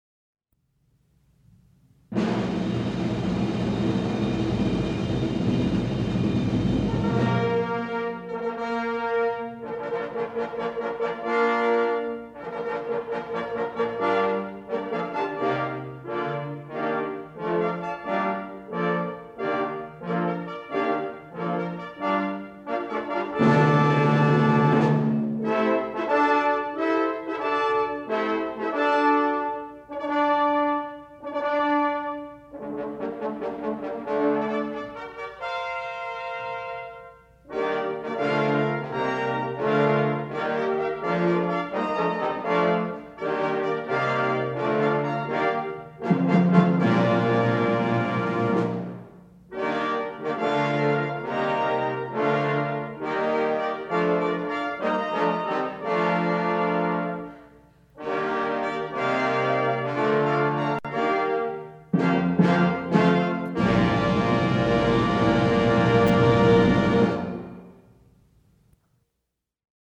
Concert Performance October 7, 1973
Audience nearly filled the house.
using a half-track, 10” reel-to-reel Ampex tape recorder
Armstrong Auditorium, Sunday at 4:00 PM
Fanfare